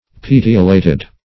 Search Result for " petiolated" : The Collaborative International Dictionary of English v.0.48: Petiolate \Pet"i*o*late\, Petiolated \Pet"i*o*la`ted\, a. (Bot.